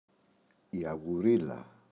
αγουρίλα, η [aγuꞋrila]